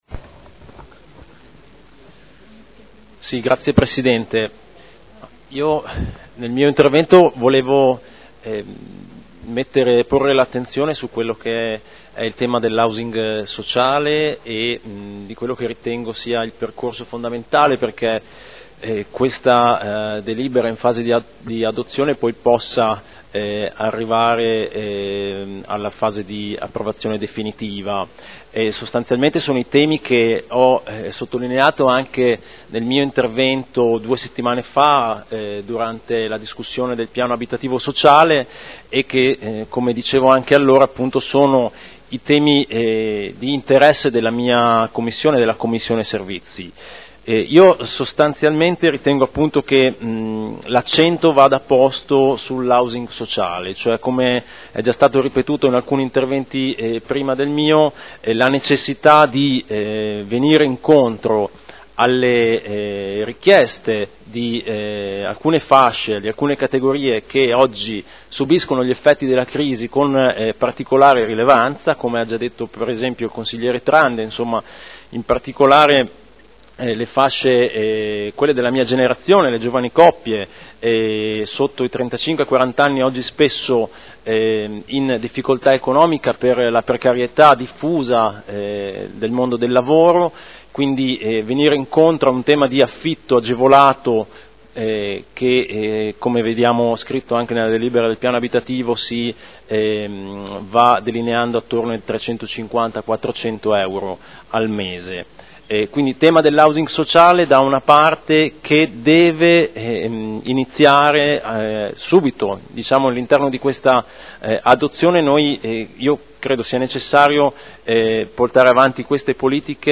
Stefano Rimini — Sito Audio Consiglio Comunale